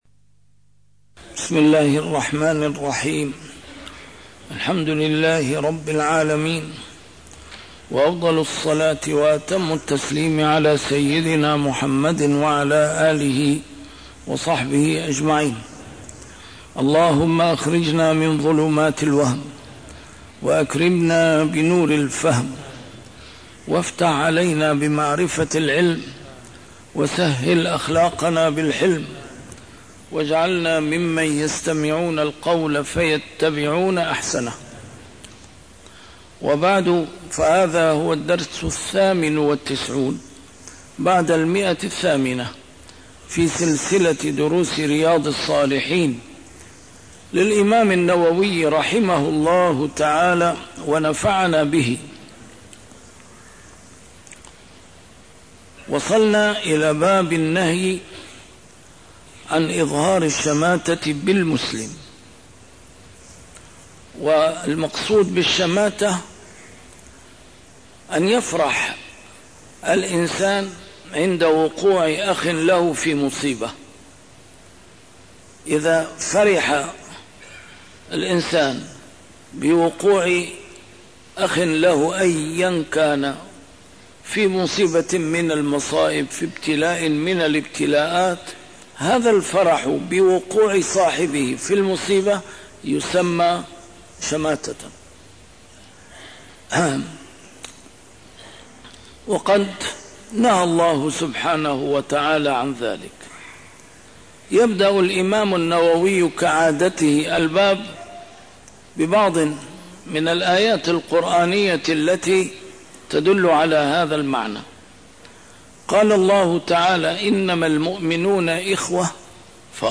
A MARTYR SCHOLAR: IMAM MUHAMMAD SAEED RAMADAN AL-BOUTI - الدروس العلمية - شرح كتاب رياض الصالحين - 898- شرح رياض الصالحين: النهي عن إظهار الشماتة بالمسلم